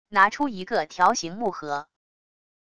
拿出一个条形木盒wav音频